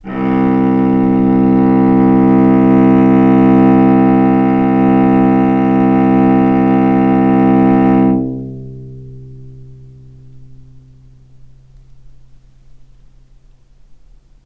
cello
vc-C2-mf.AIF